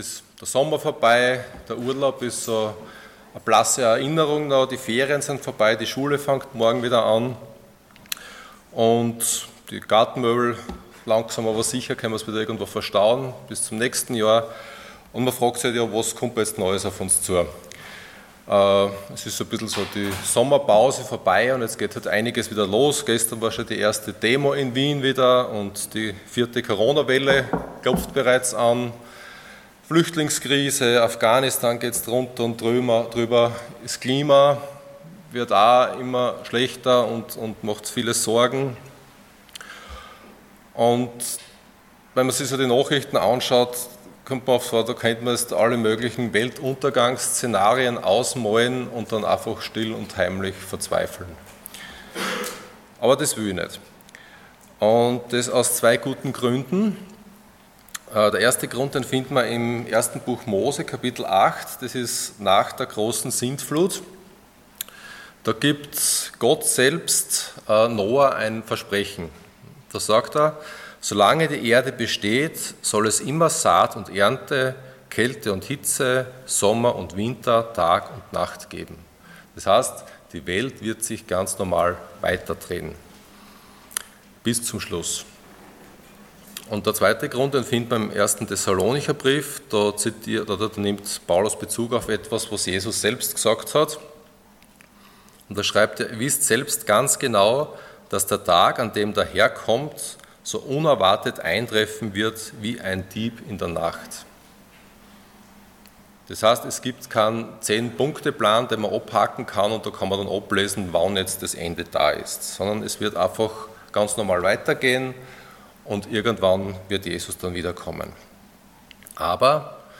Passage: Joshua 3:1-4:24 Dienstart: Sonntag Morgen